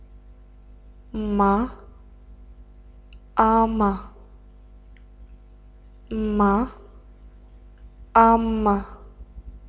Voiced Sounds of the Romanian Language
Consonants - Speaker #2